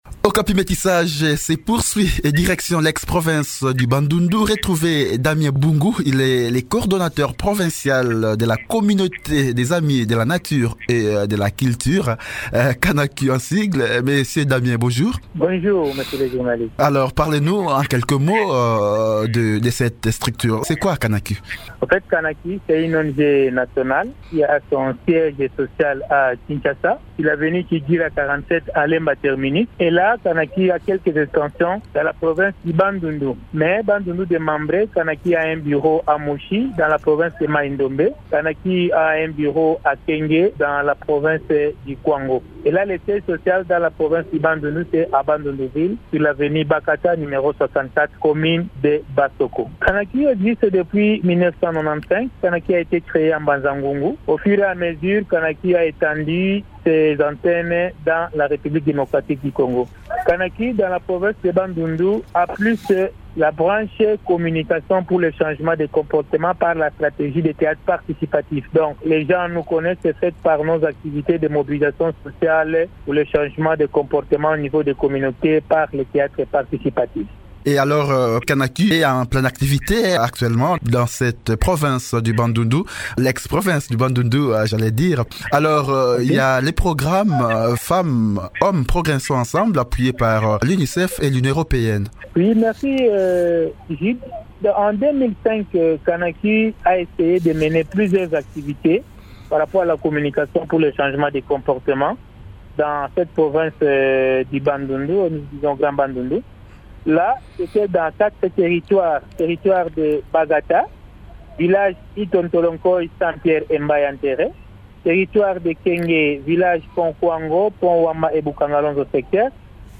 en parle au micro de